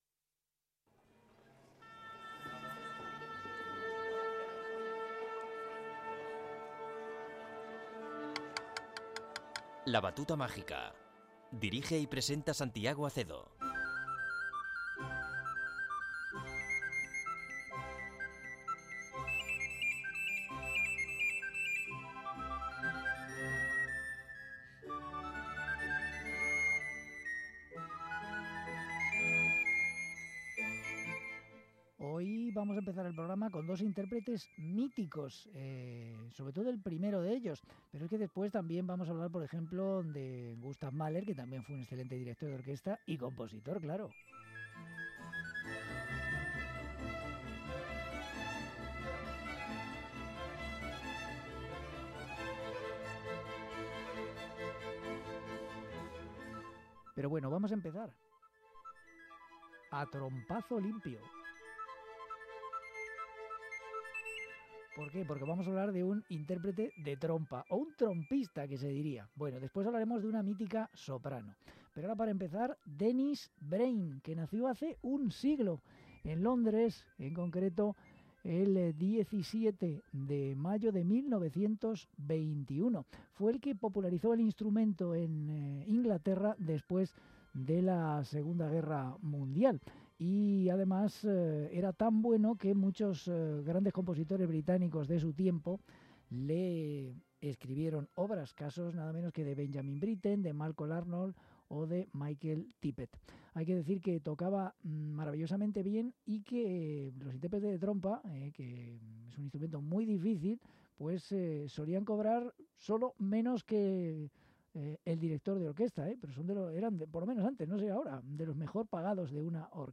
trompista
la soprano australiana
Concierto para Piano
Concierto para 2 oboes